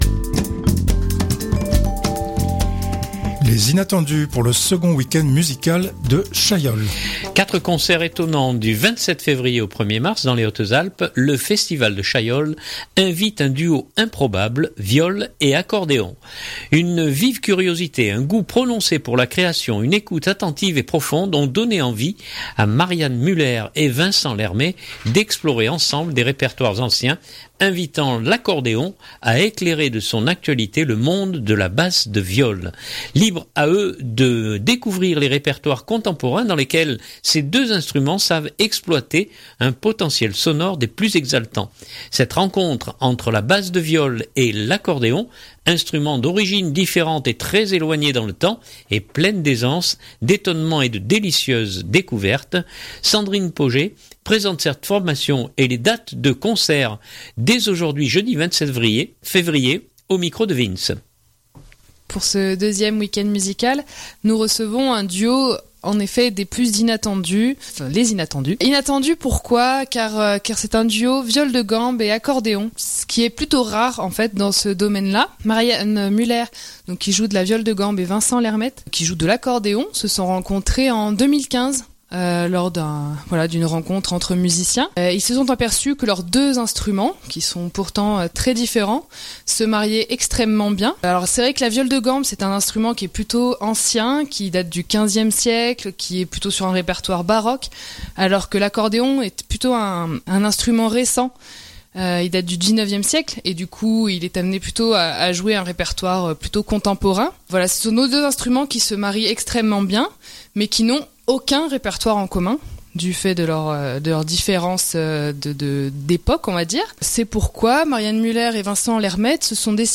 WEM 2 Chaillol.mp3 (4.38 Mo) Quatre concerts étonnants du 27 février au 1er mars dans les Hautes-Alpes. Le Festival de Chaillol invite un duo improbable viole et accordéon.